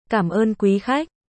1. Âm thanh lời chào tiếng việt